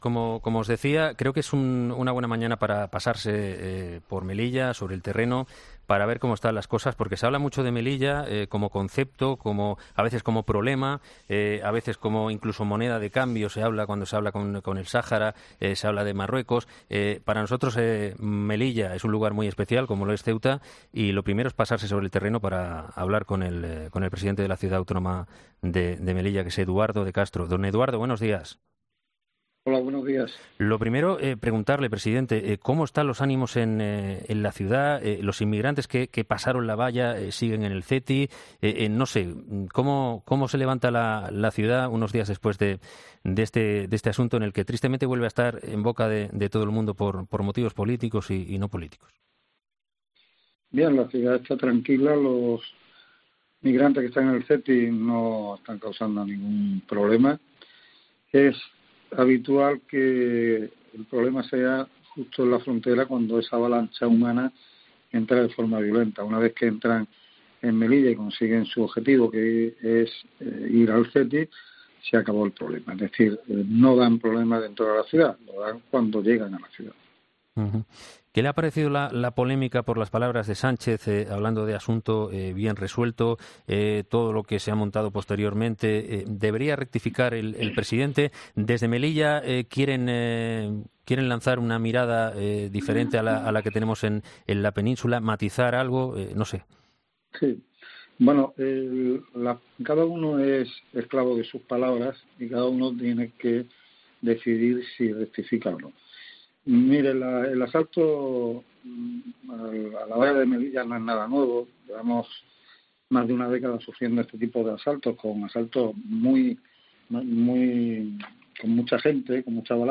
El presidente de Melilla habla sobre los problemas existentes en nuestras fronteras con Marruecos con los migrantes